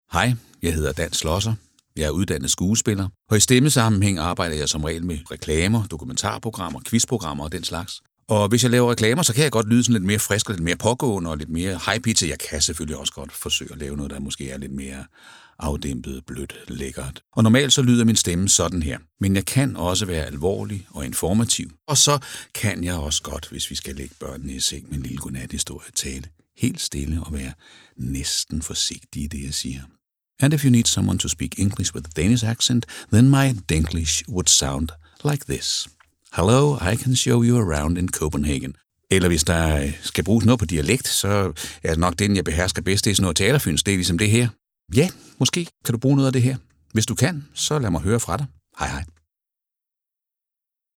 Stemmeprøver/samples